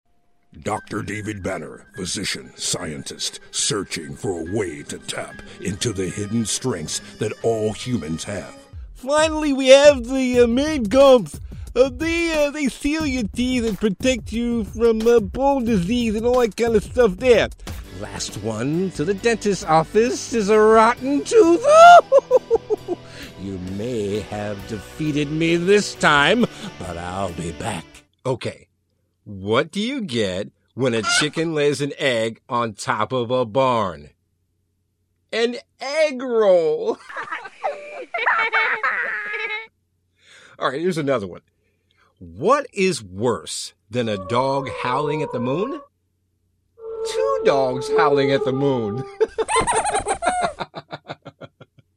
a Kings Voice delivers clear, engaging voice overs for brands like TD Ameritrade and Louisiana Tourism, plus audiobook productions.